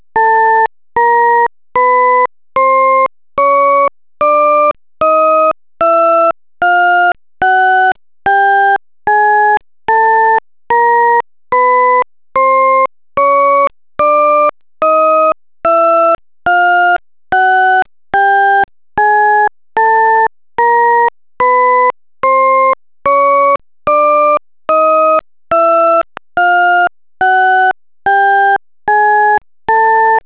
shepard30.au